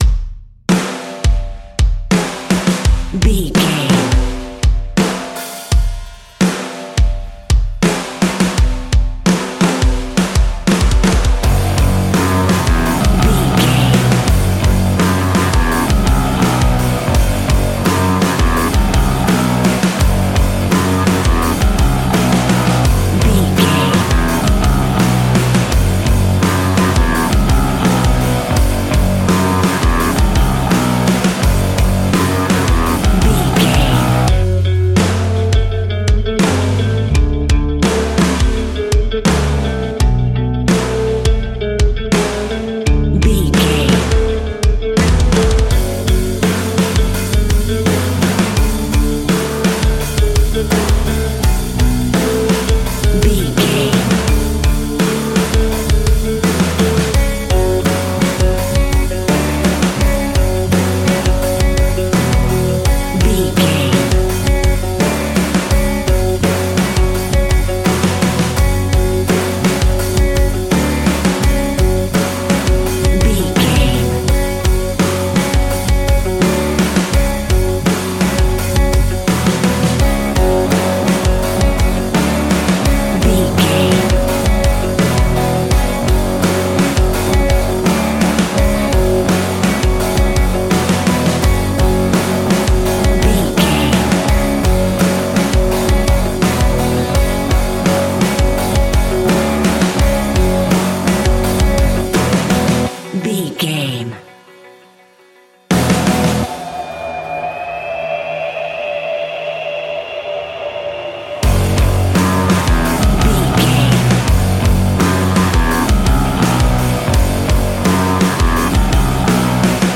Ionian/Major
F♯
hard rock
guitars
heavy metal
instrumentals